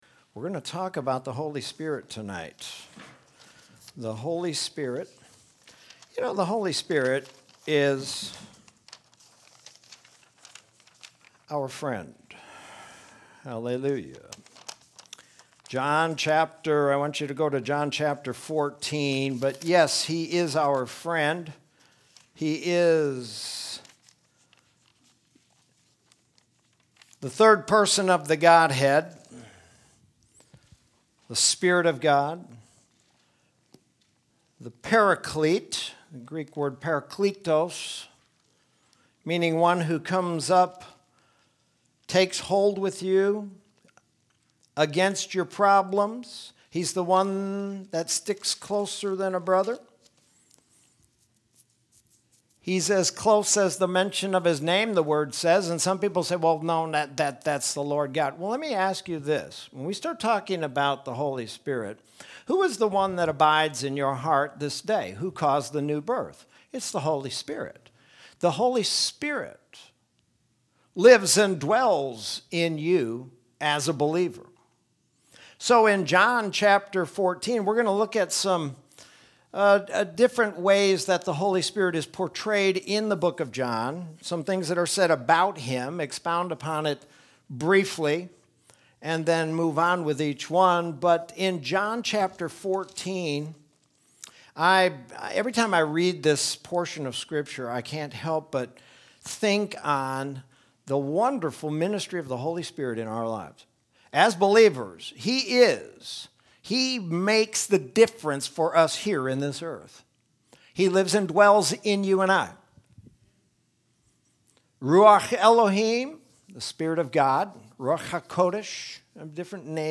Sermon from Wednesday, September 9th, 2020.